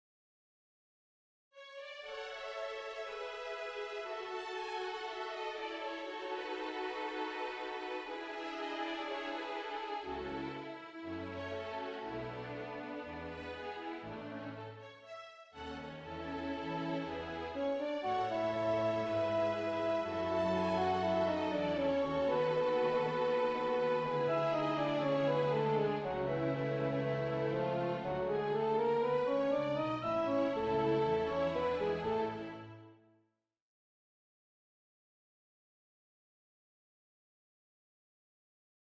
Le concerto pour piano et orchestre.
On ne peut ici qu’admirer la science de l’écriture alliée à une prodigieuse invention mélodique.